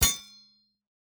Drop Metal A.wav